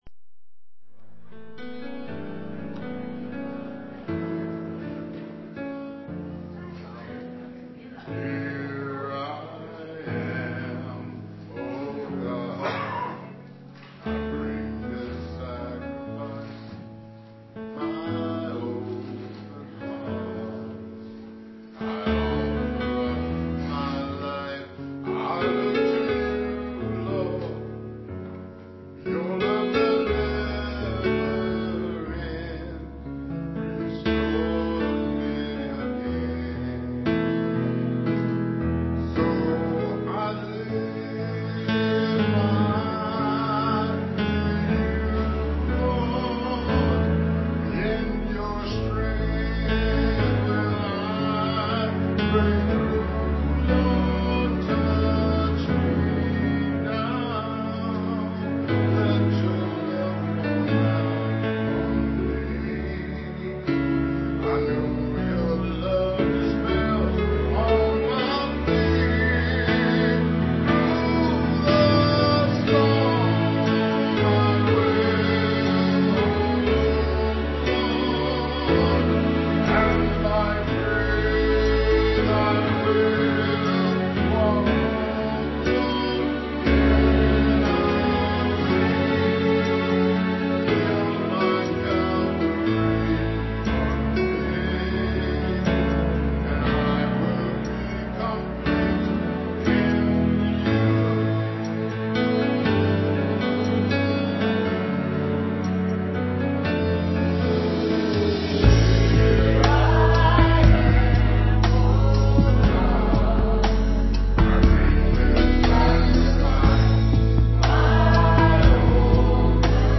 Piano and organ offertory